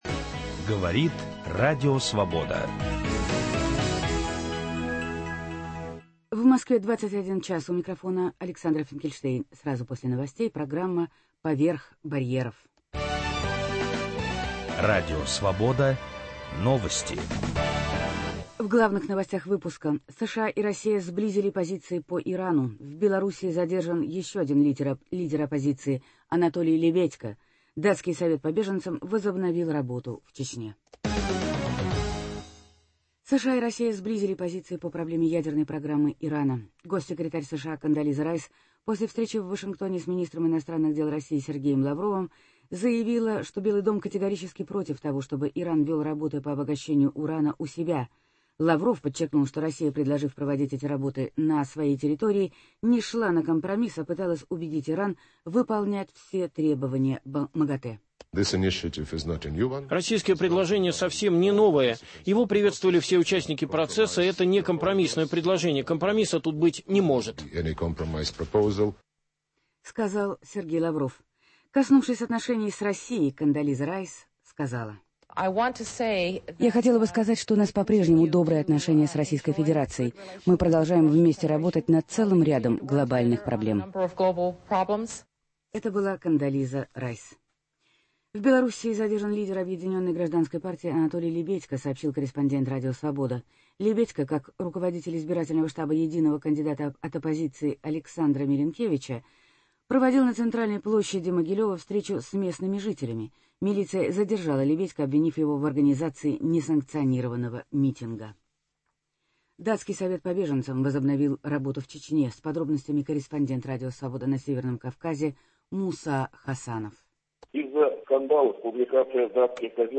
Интервью.